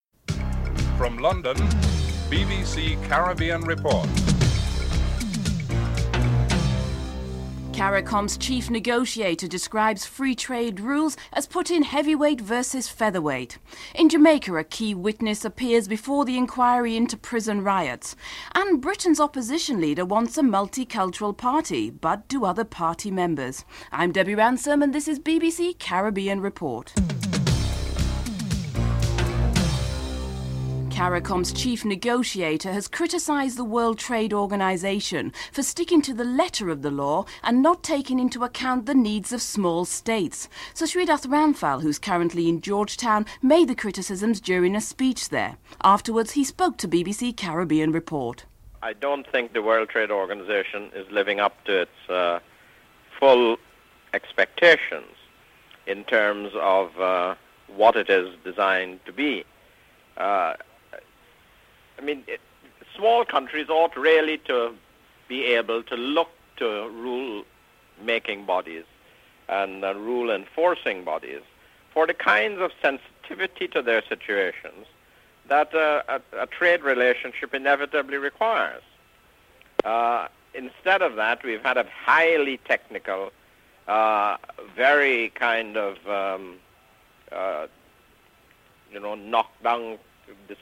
1. Headlines (00:00-00:29)
2. CARICOM's chief negotiator describes free trade rules as putting heavy weight versus feather weight. Caricom's Chief Negoiator, Sir Shridath is interviewed (00:30-04:36)